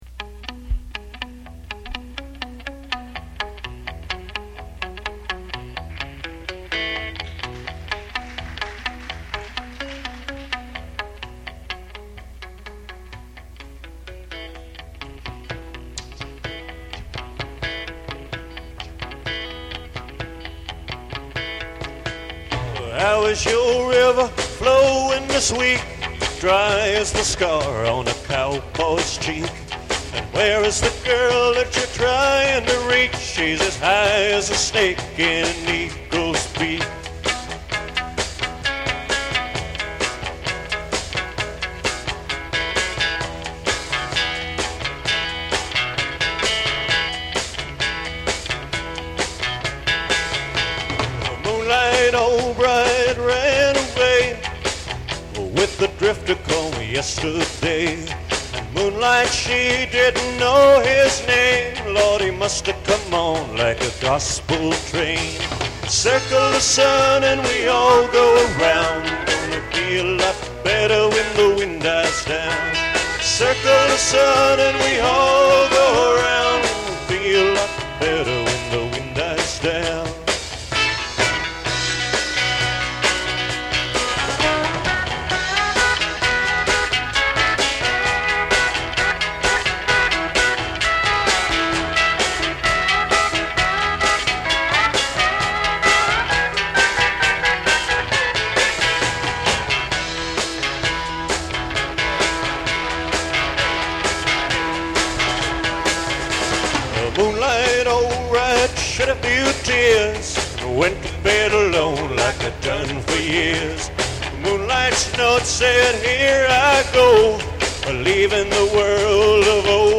Selected Gig